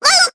Cleo-Vox_Damage_jp_03.wav